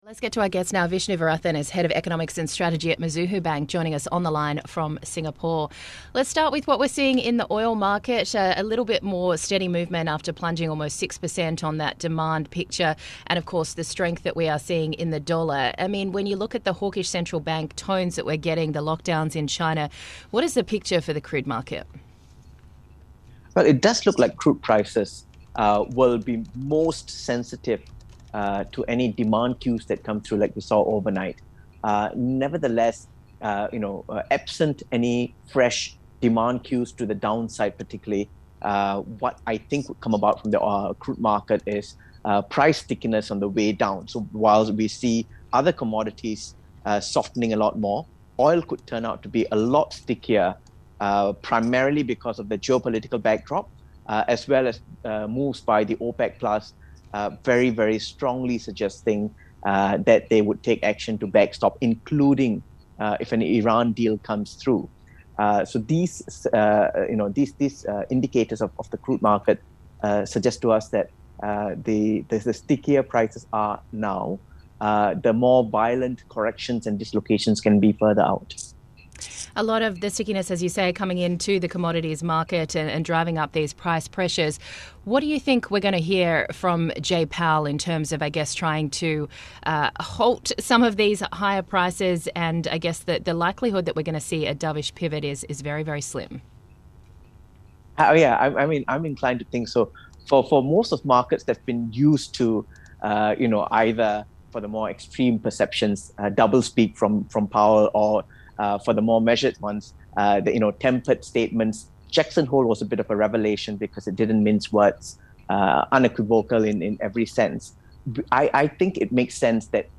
(Radio) - Bloomberg Daybreak: Asia Edition